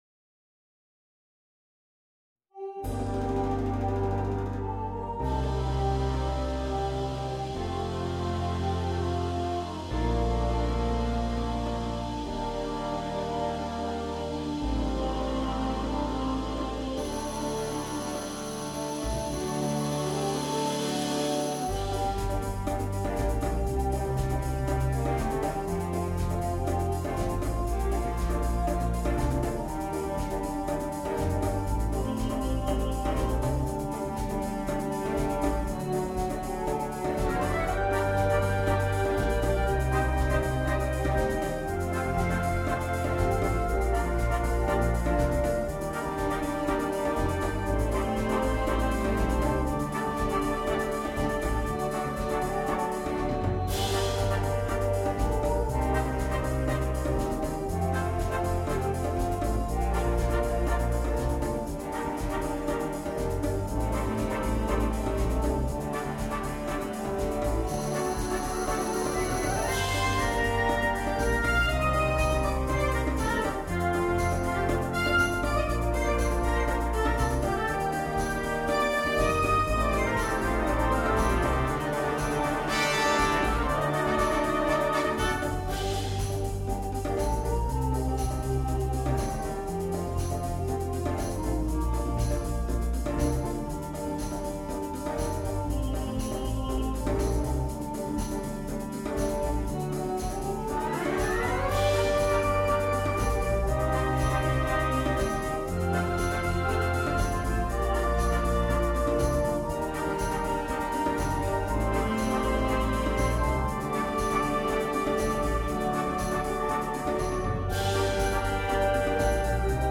на духовой оркестр.